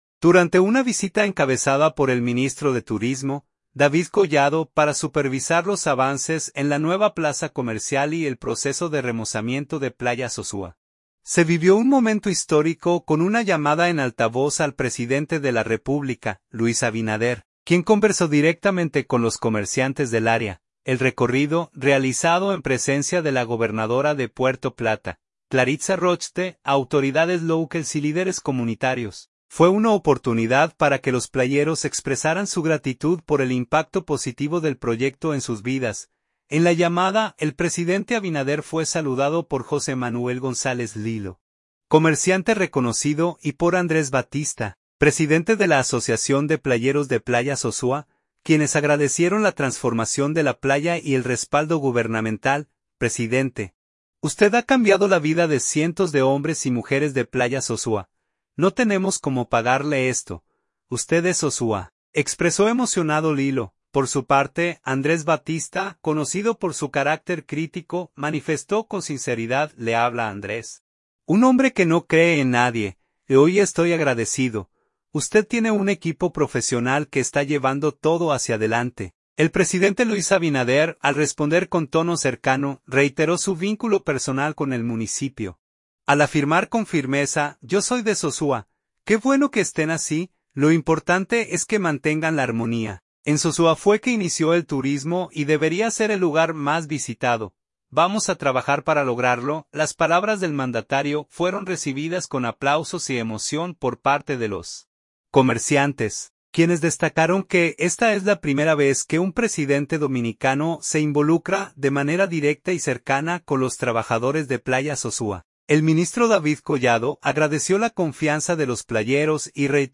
Durante una visita encabezada por el ministro de Turismo, David Collado, para supervisar los avances en la nueva plaza comercial y el proceso de remozamiento de Playa Sosúa, se vivió un momento histórico con una llamada en altavoz al presidente de la República, Luis Abinader, quien conversó directamente con los comerciantes del área.
El presidente Luis Abinader, al responder con tono cercano, reiteró su vínculo personal con el municipio, al afirmar con firmeza:
Las palabras del mandatario fueron recibidas con aplausos y emoción por parte de los comerciantes, quienes destacaron que esta es la primera vez que un presidente dominicano se involucra de manera directa y cercana con los trabajadores de Playa Sosúa.